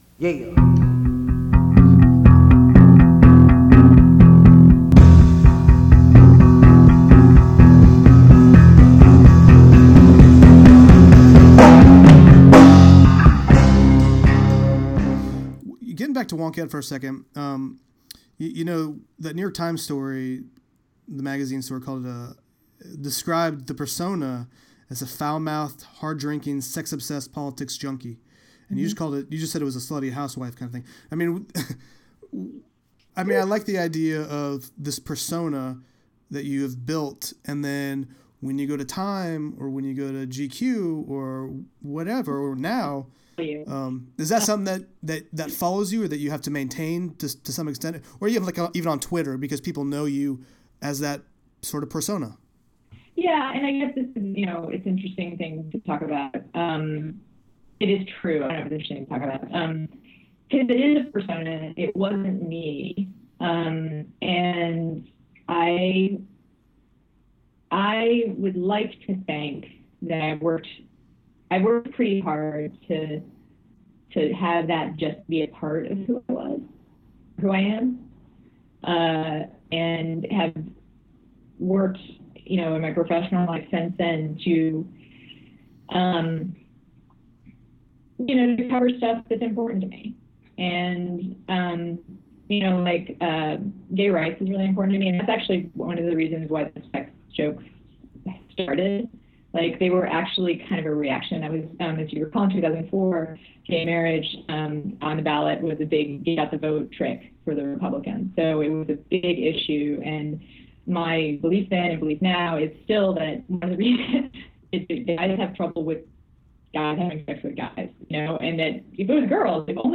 We continue our conversation with Ana Marie Cox, a founding editor at Wonkette who’s now a political columnist at The Guardian, in the second-part of this two-part episode (check out the first part here). In this edition, we talk about whether she had to maintain the persona she originally brought to Wonkette once she moved on from the website, if and when male and female journalists will ever be on equal ground when it comes to how readers view them in social media, and how the divisiveness in sports compares to the divisiveness in politics.